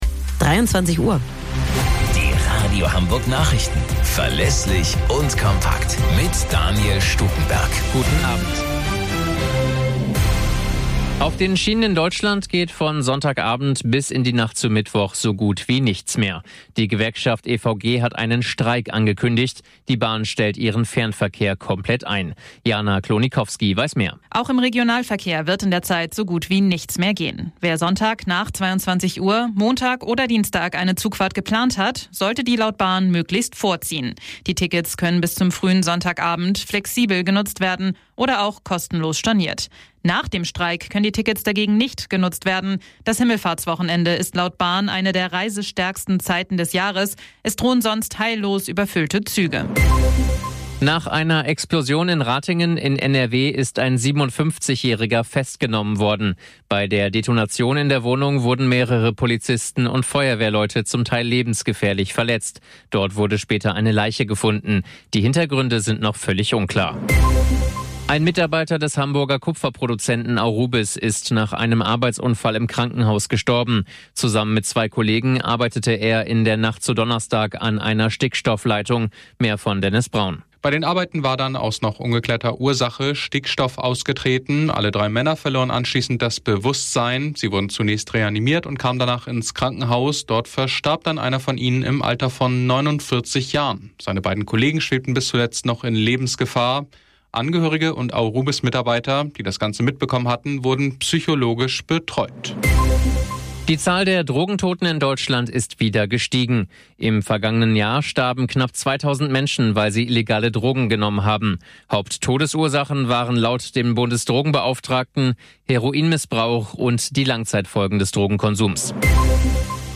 Radio Hamburg Nachrichten vom 20.07.2023 um 01 Uhr - 20.07.2023